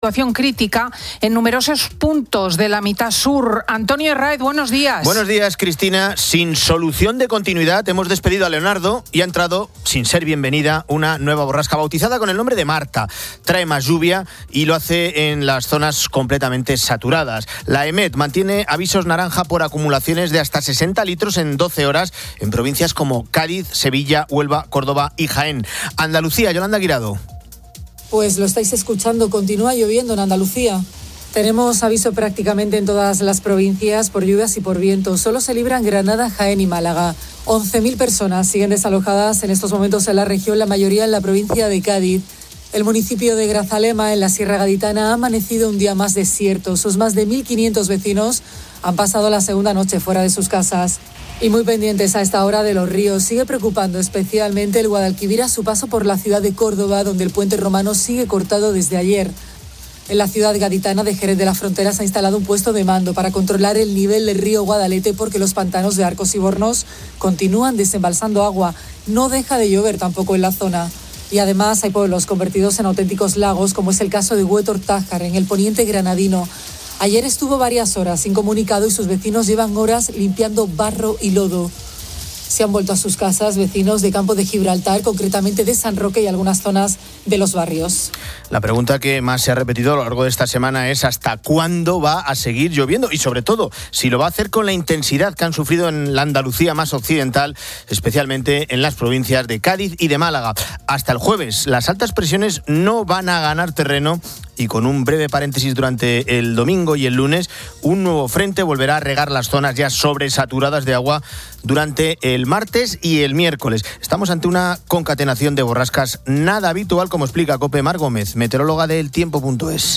Fin de Semana 10:00H | 07 FEB 2026 | Fin de Semana Editorial de Cristina López Schlichting.